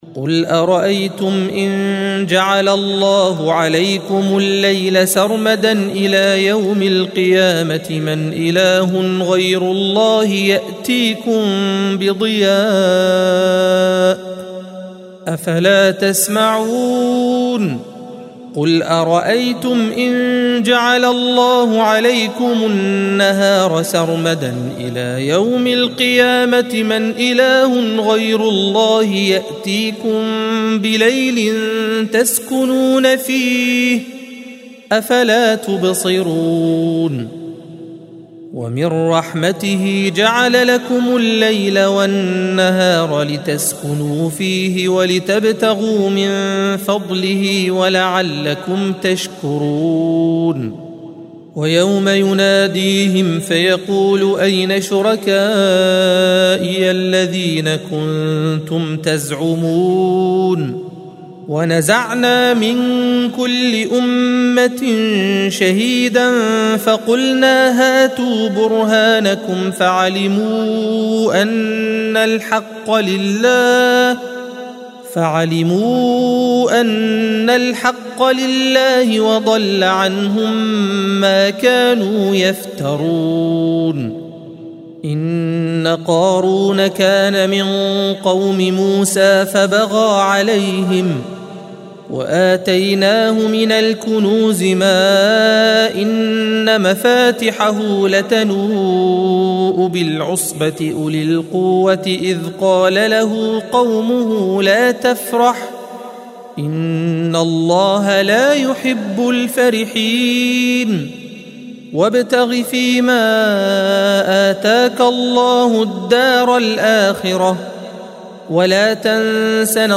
الصفحة 394 - القارئ